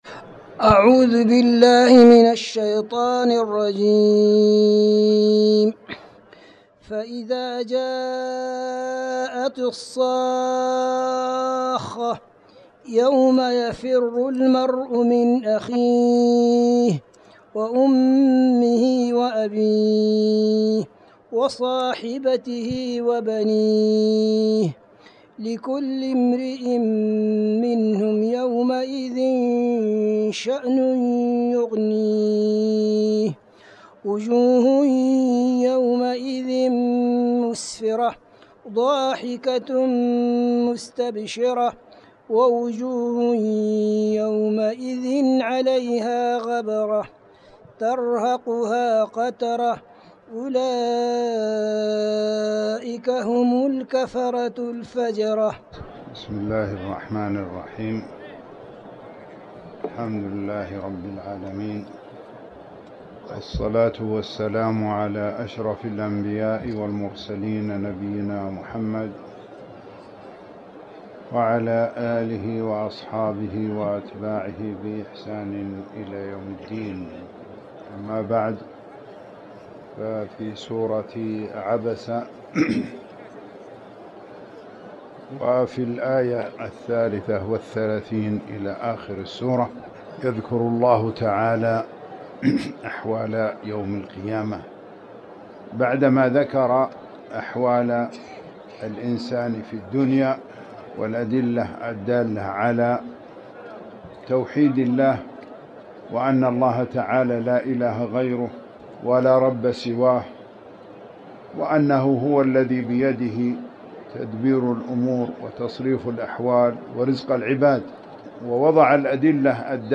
تاريخ النشر ١٣ جمادى الآخرة ١٤٤٠ هـ المكان: المسجد الحرام الشيخ